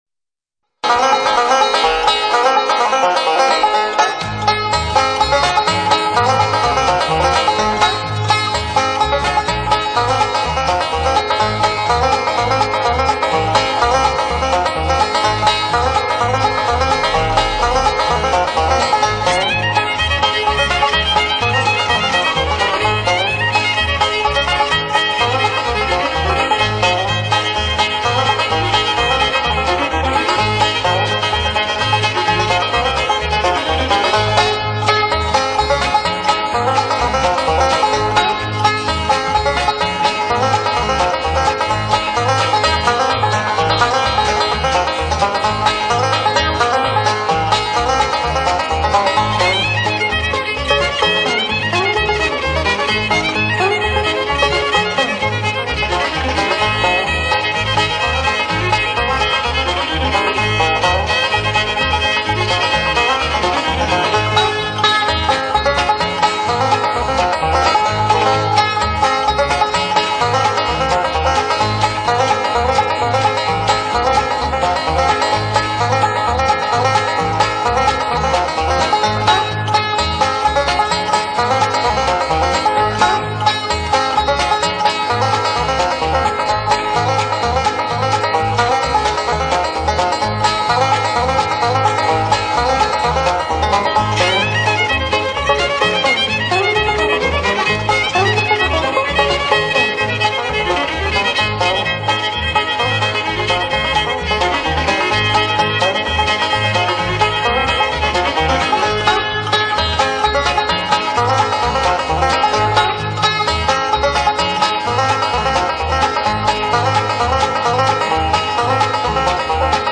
'블루그래스(Bluegrass)'라고 하는 음악입니다